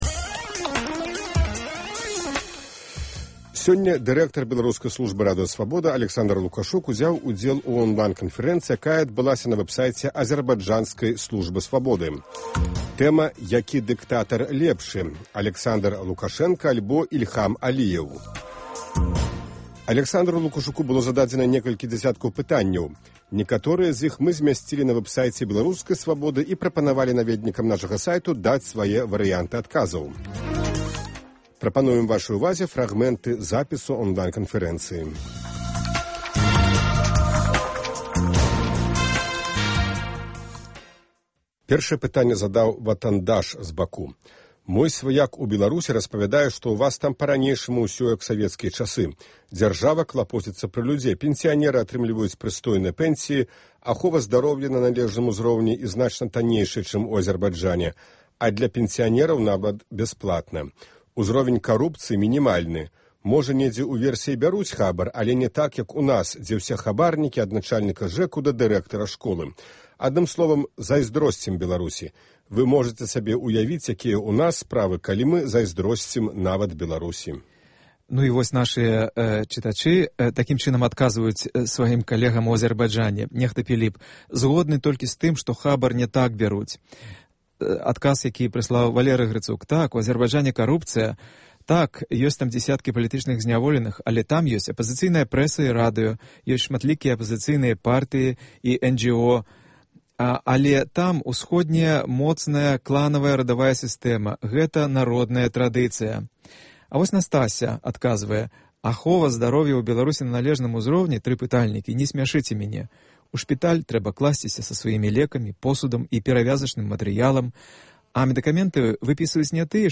Онлайн- канфэрэнцыя
Запіс онлайн-канфэрэнцыі